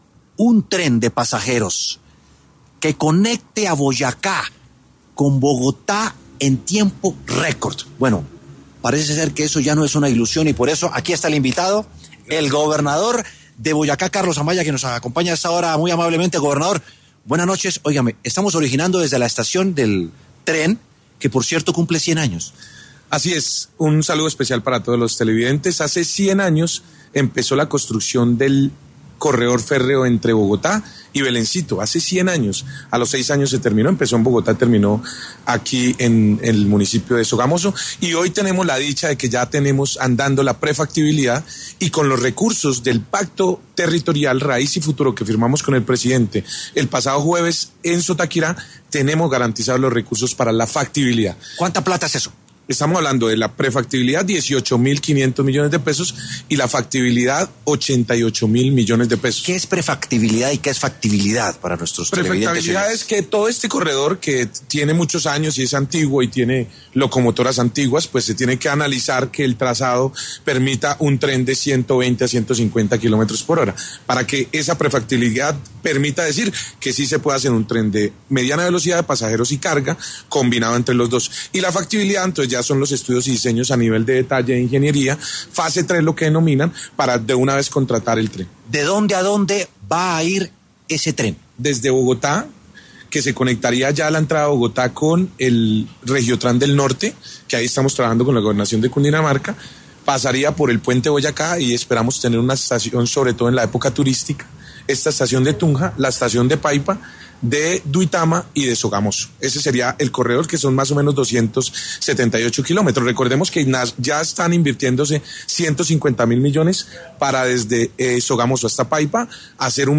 Carlos Amaya, gobernador de Boyacá, pasó por los micrófonos de W Sin Carreta y habló sobre el tren que promete conectar Boyacá con Bogotá en tiempo récord.